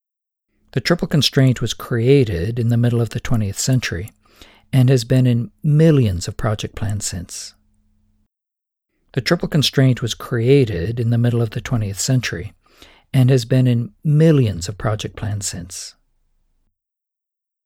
Hi, I’ve recorded 10 hours of a course on project management, however much of it has tiny clicks throughout, I think just from my voice.
…Is there some way to create some kind of custom filter or process that targets just these tiny clicks?